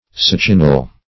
Definition of succinyl.
Search Result for " succinyl" : The Collaborative International Dictionary of English v.0.48: Succinyl \Suc"cin*yl\, n. [Succinic + -yl.]